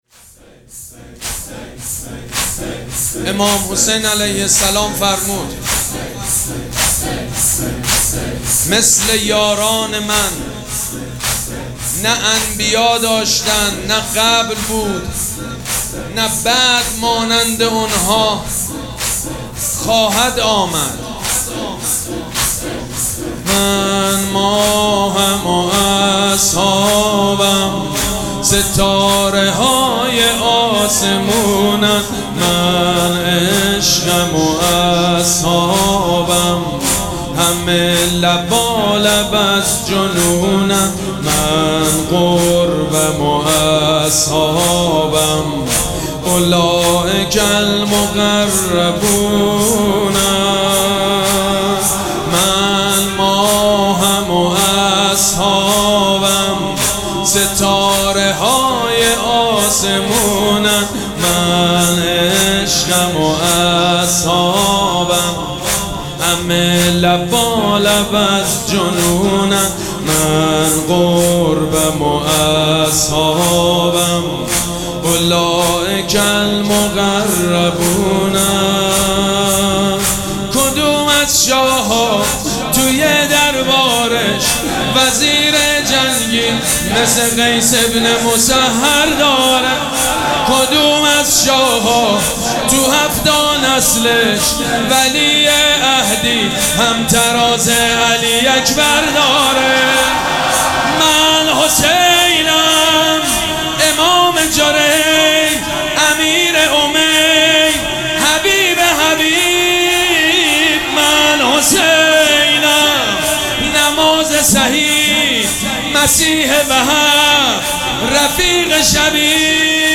مراسم عزاداری شب چهارم محرم الحرام ۱۴۴۷
مداح
حاج سید مجید بنی فاطمه